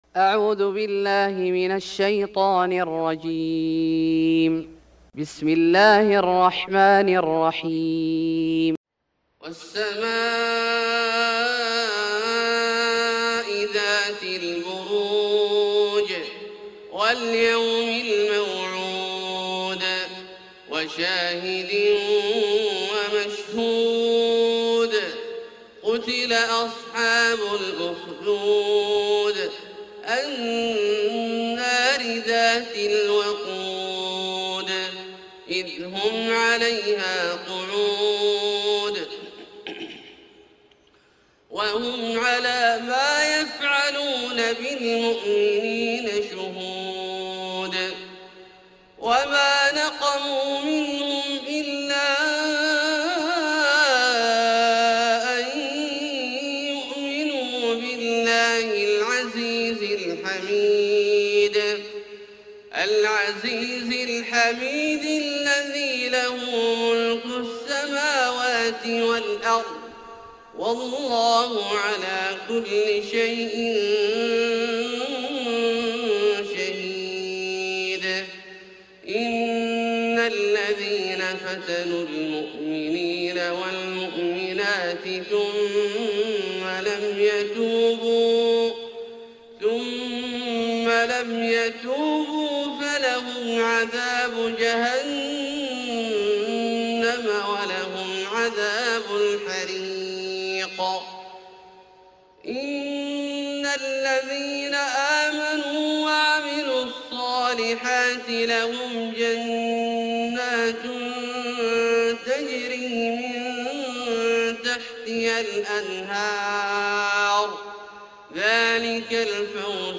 سورة البروج Surat Al-Buruj > مصحف الشيخ عبدالله الجهني من الحرم المكي > المصحف - تلاوات الحرمين